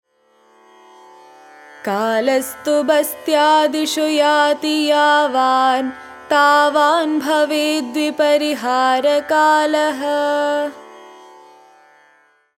IMPORTANT SLOKA